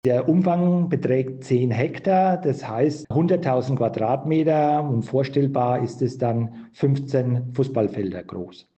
Wir sprechen mit Ulrich Werner, dem Bergrheinfelder Bürgermeister.